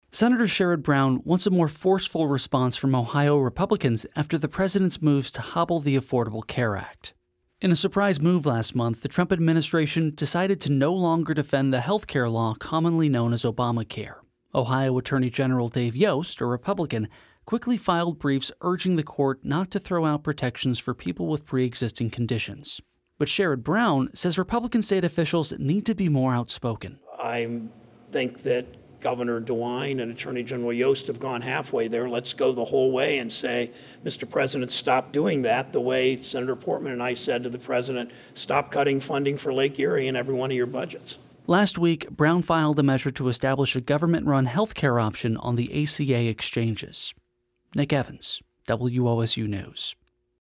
Speaking at John R. Maloney Health Center on Columbus' South Side, Brown argued the stakes of allowing the Texas judge’s ruling to stand are monumental.